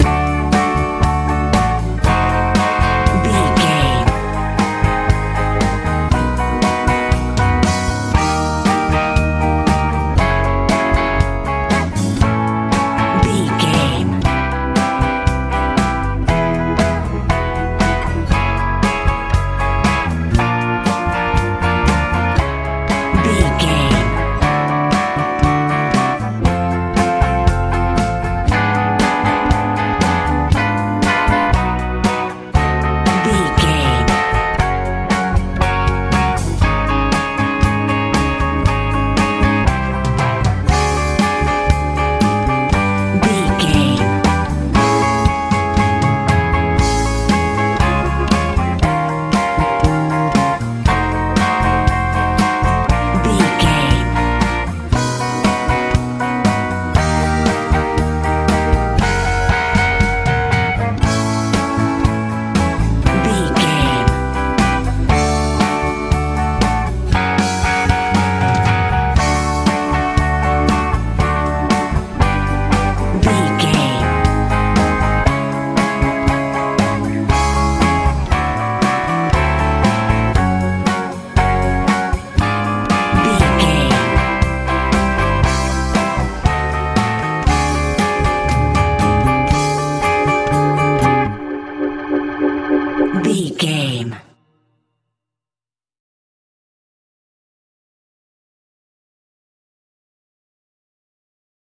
med rock feel
Ionian/Major
D
driving
groovy
organ
bass guitar
electric guitar
drums
joyful
energetic